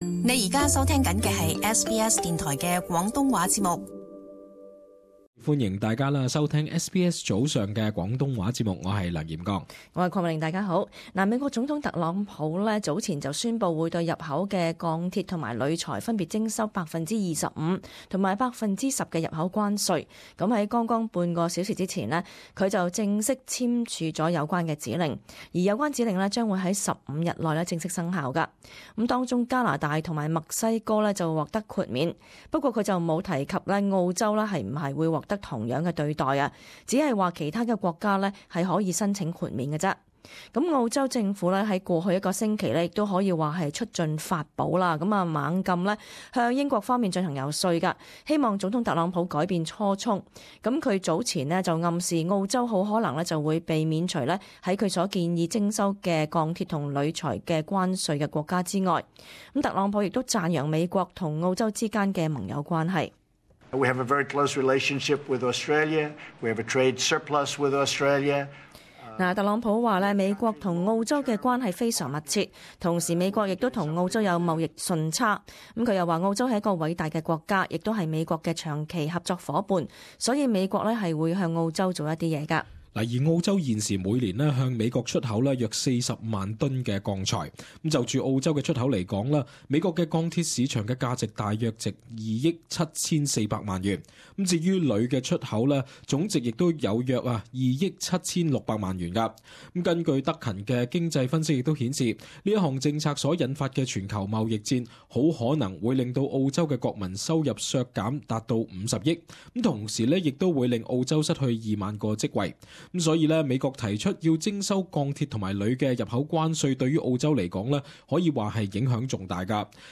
【时事报导】美国落实钢铁及铝材进口关税计划